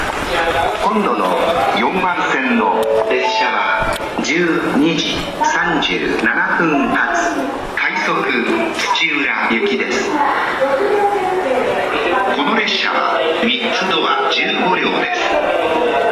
ただ、京成線・新幹線・宇都宮線・高崎線の走行音で発車メロディの収録は困難です。
次発放送